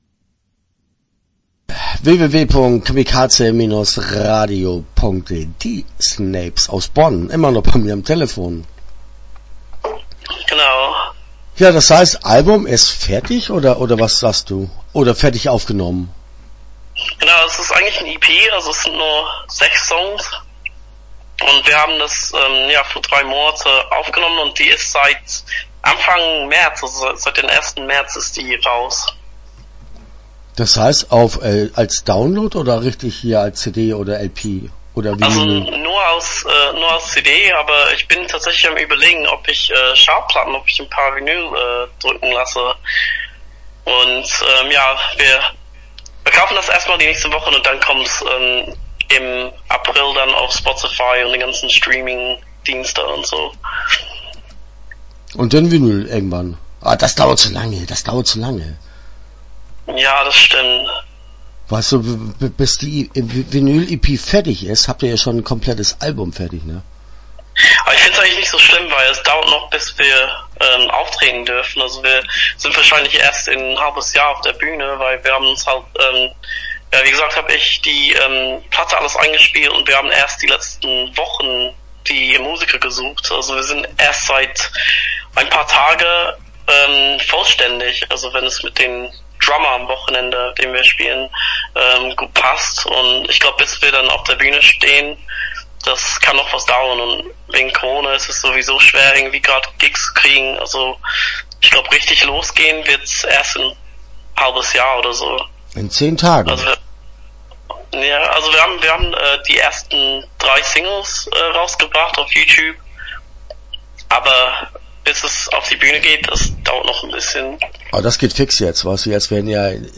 Start » Interviews » Die Snapes